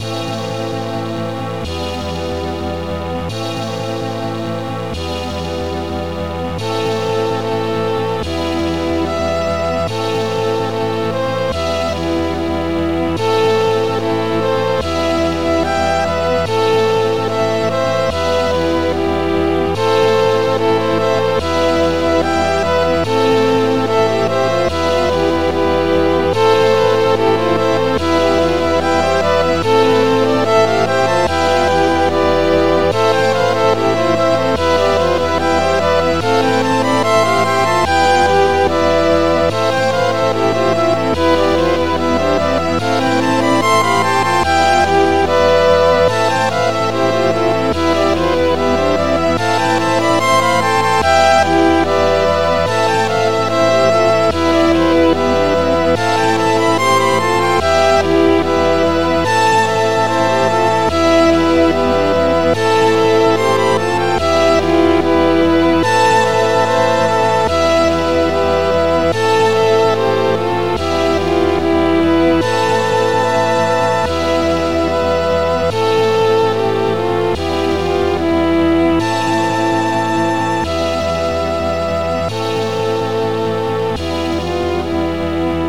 Extended Module
2 channels
Pipey thingys 303:Violin